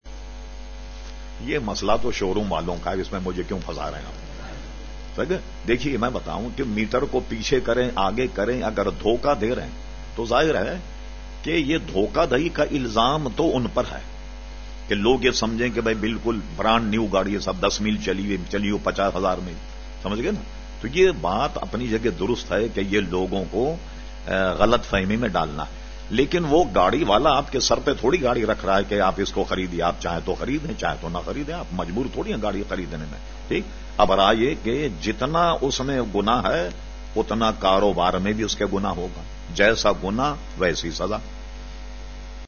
Q/A Program held on Sunday 26 February 2012 at Masjid Habib Karachi.